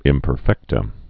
(ĭmpər-fĕktə)